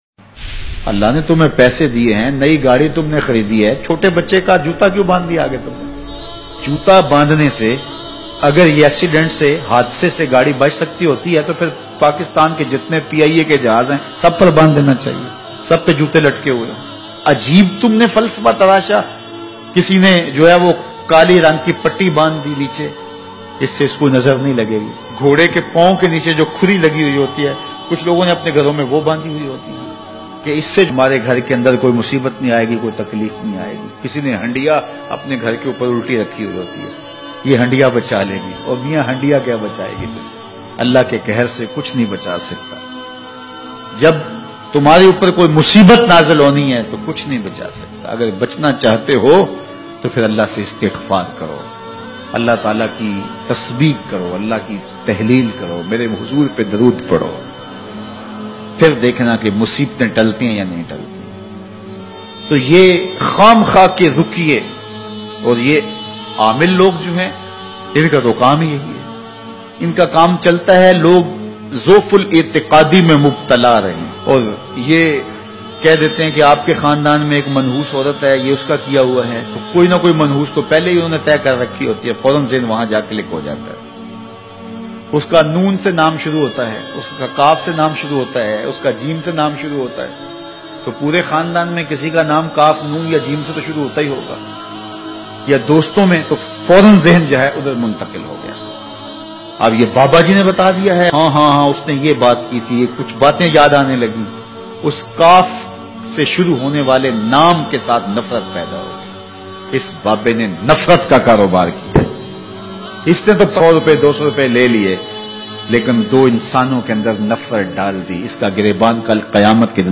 Karobar ma barkat kaisy ho gi bayan mp3
karobar me barkat islamicdb bayan SRM.mp3